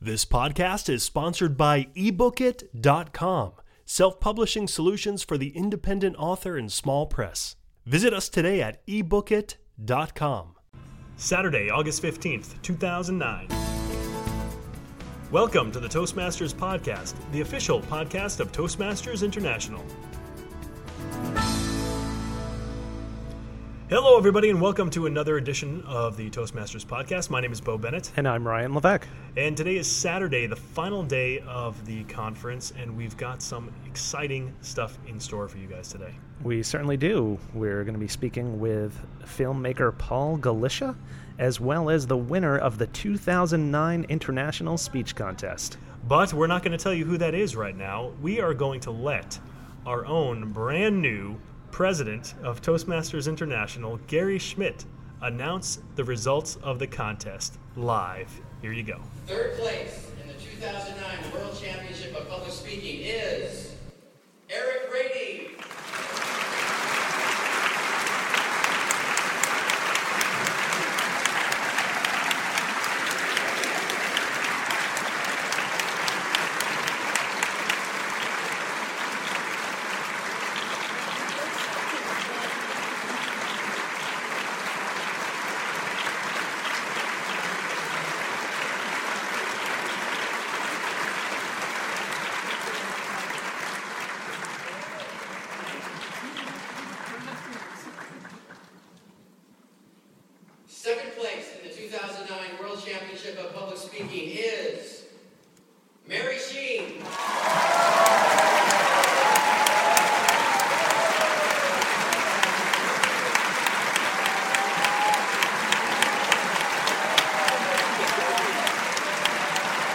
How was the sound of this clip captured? Part 3 of our coverage from the 2009 International Convention